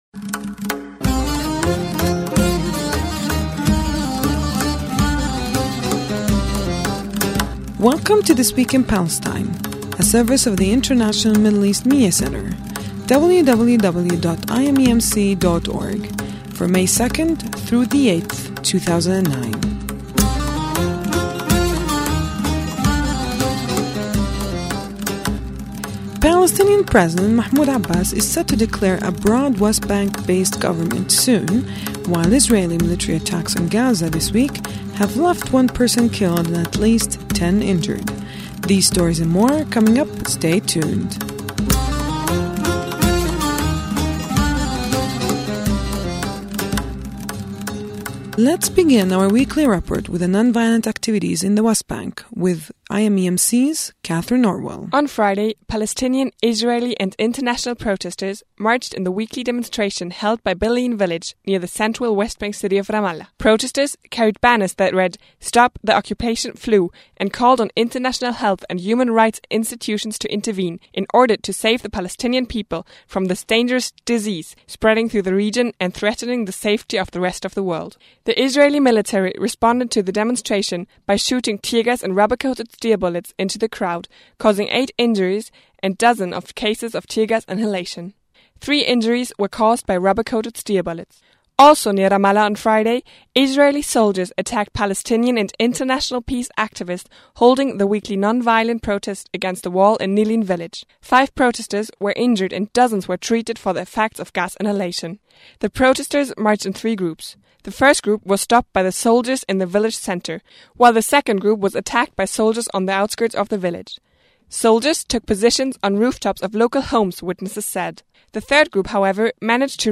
Audio Dept. | 08.05.2009 16:26 | Palestine | World